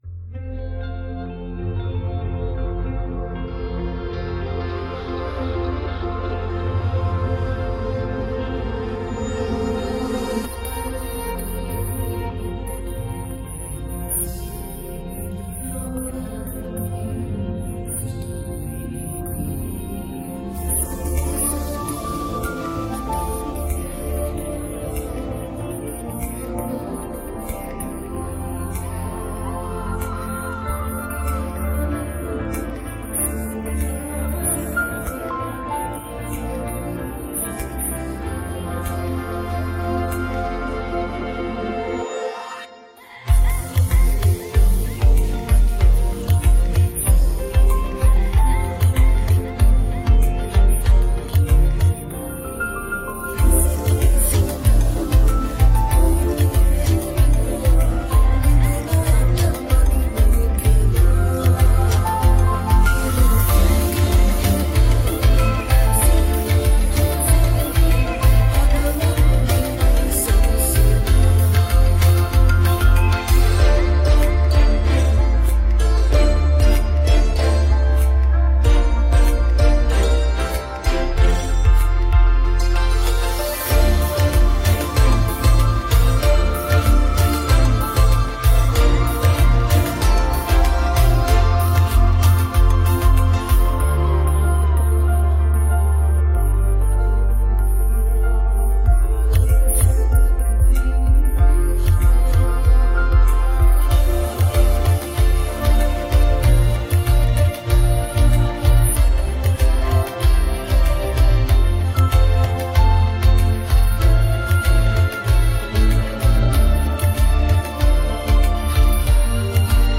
Sing with Us
Play karaoke & Sing with Us